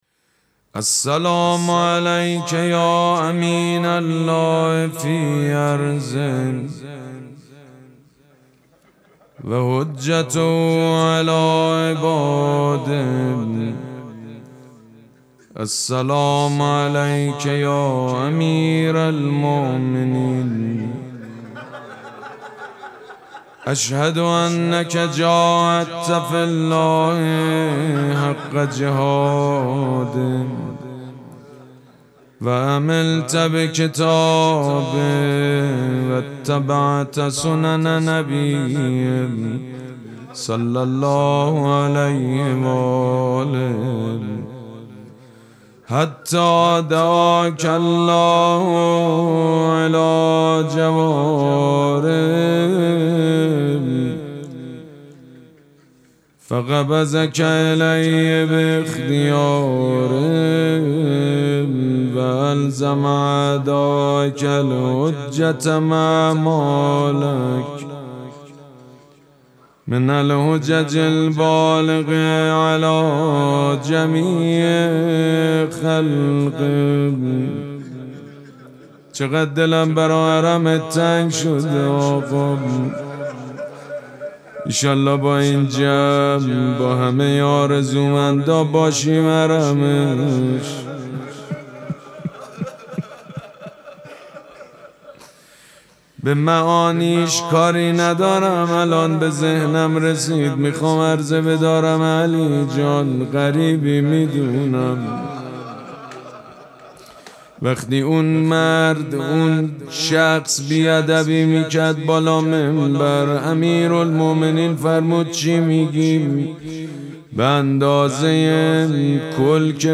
مراسم مناجات شب پنجم ماه مبارک رمضان چهارشنبه‌ ۱۵ اسفند ماه ۱۴۰۳ | ۴ رمضان ۱۴۴۶ ‌‌‌‌‌‌‌‌‌‌‌‌‌هیئت ریحانه الحسین سلام الله علیها
مداح حاج سید مجید بنی فاطمه